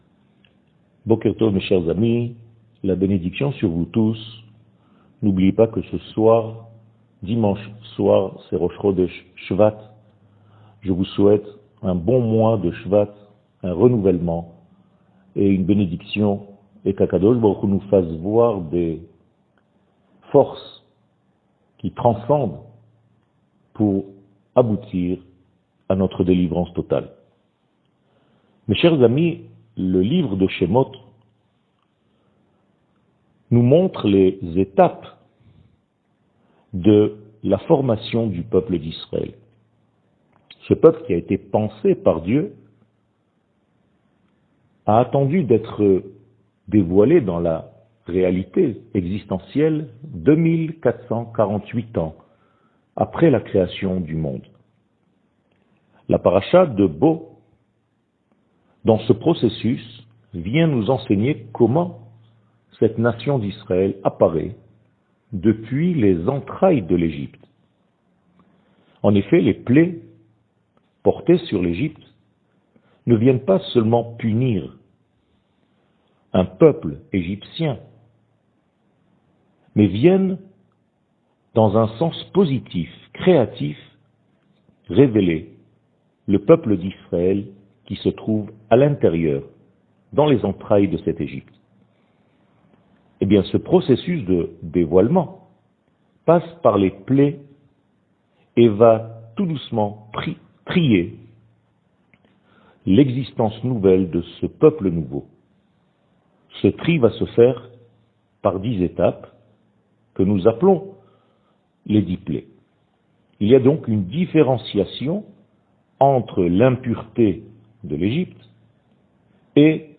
שיעורים קצרים